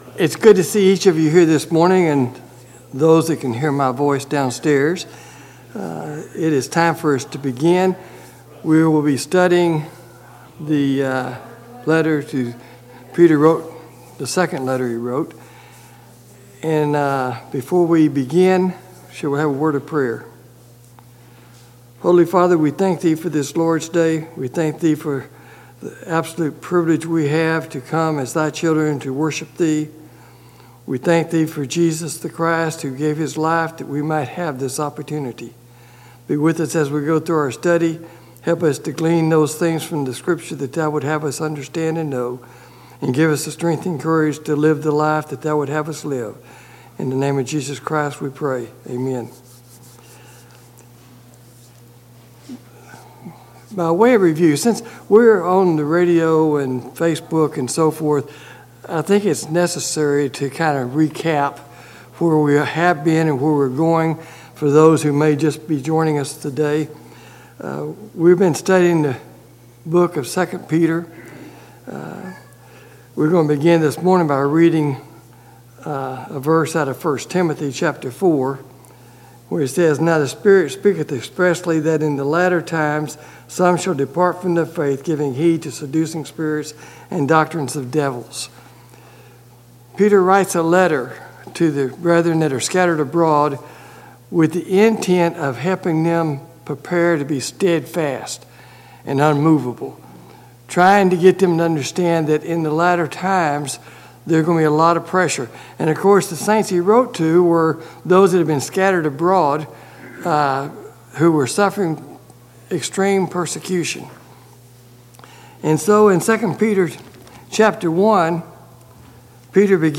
Bible Study: II Peter 3:1-12
Service Type: Sunday Morning Bible Class